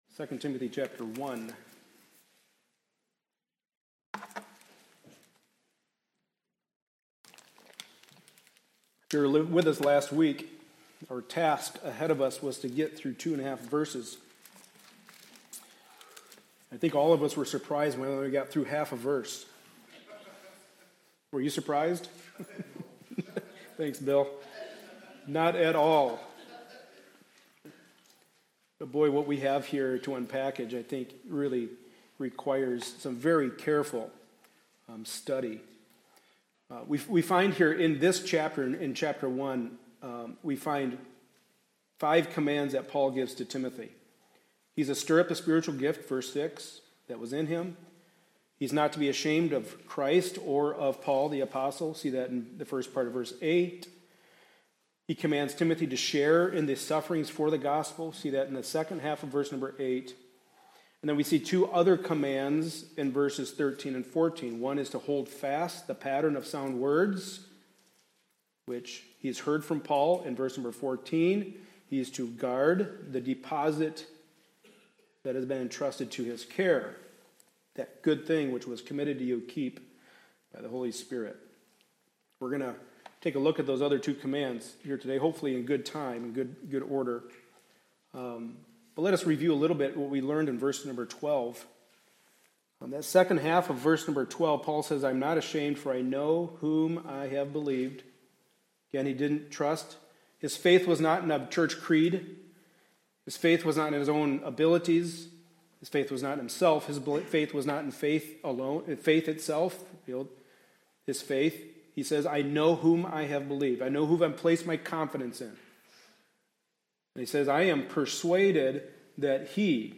2 Timothy 1:1-14 Service Type: Sunday Morning Service A study in the Pastoral Epistles.